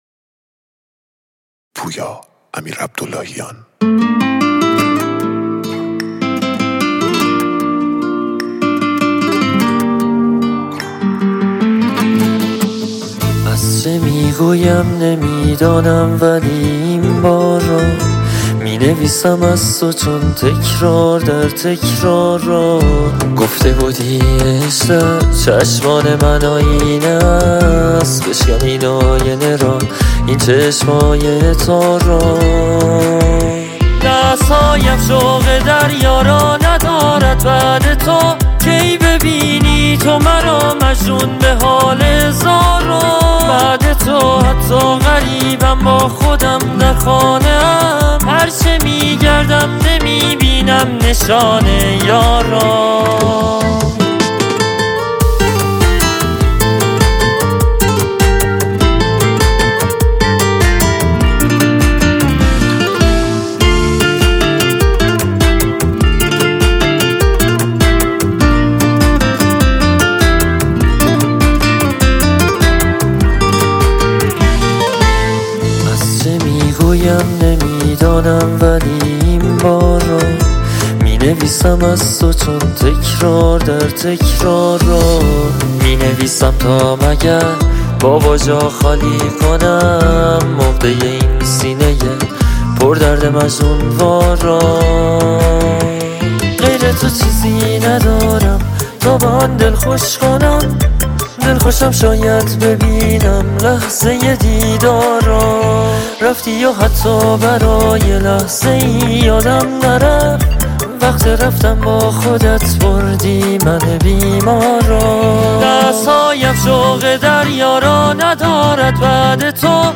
آهنگهای پاپ فارسی
کیفیت بالا